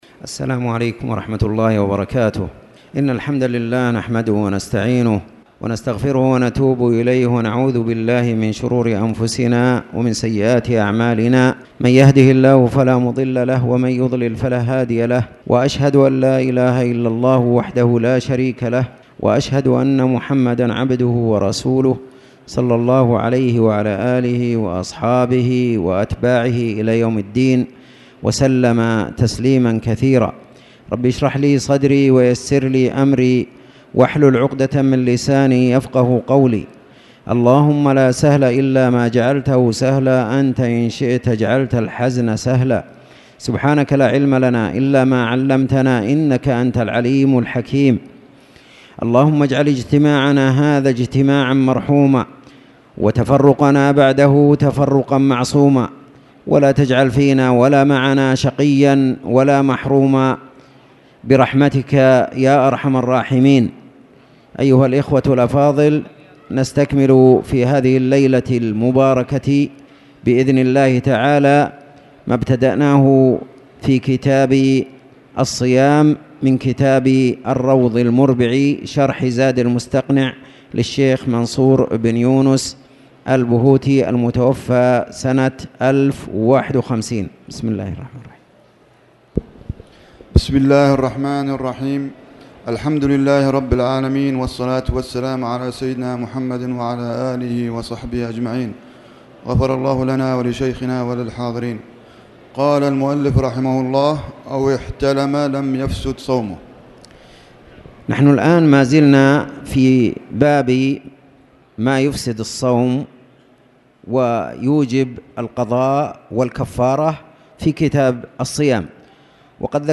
تاريخ النشر ٤ ربيع الثاني ١٤٣٨ هـ المكان: المسجد الحرام الشيخ